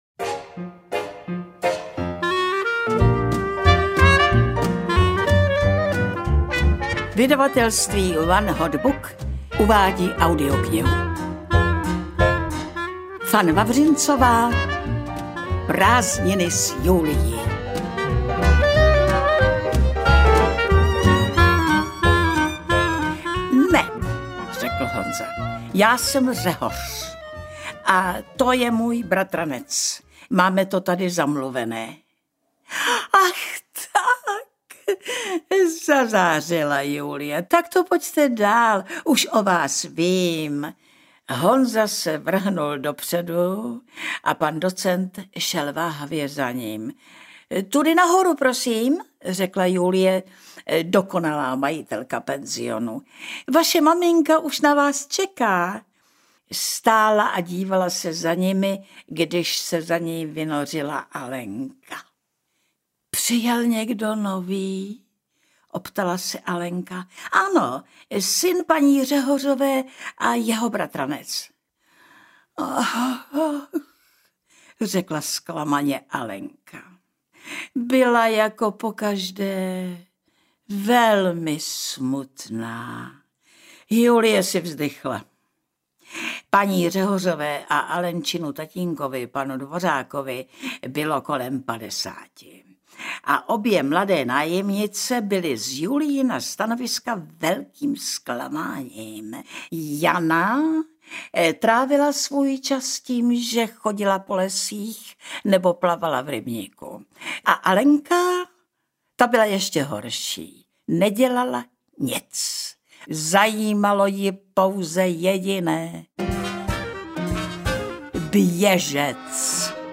Audiobook
Read: Alena Vránová